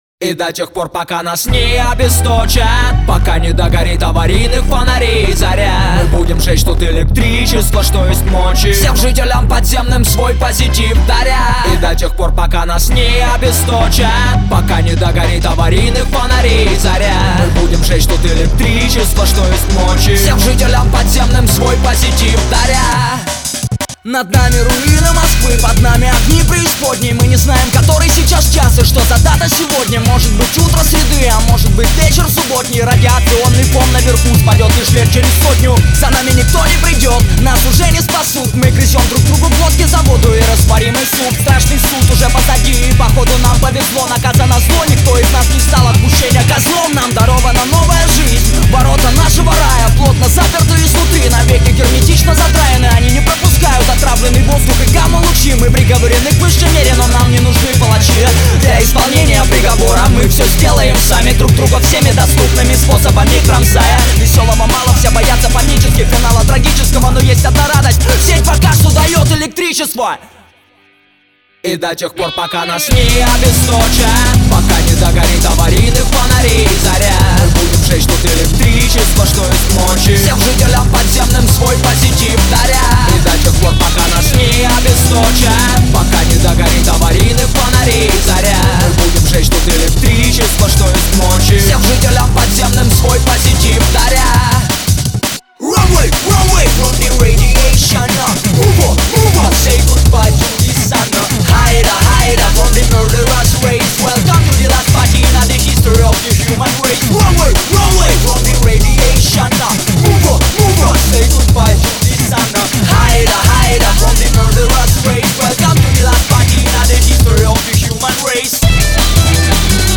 Категория: Рэп, хип-хоп